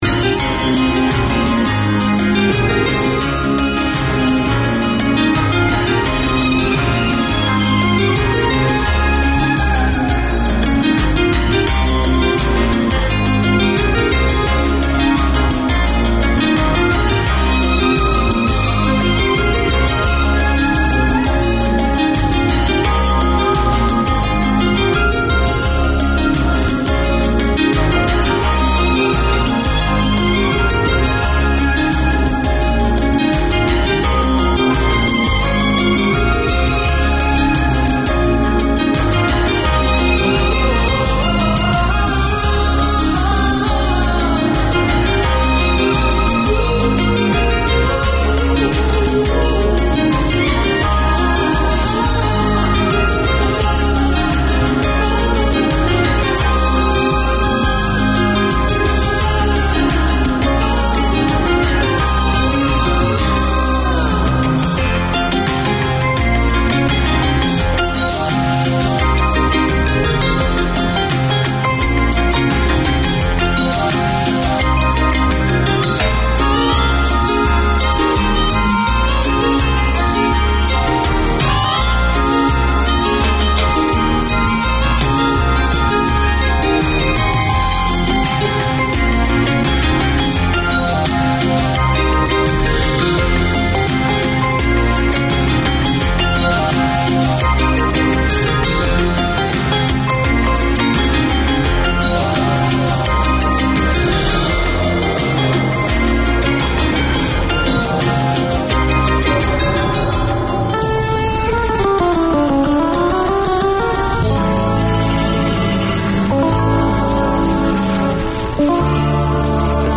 Community Access Radio in your language - available for download five minutes after broadcast.
The Filipino Show No shows scheduled this week Community magazine Language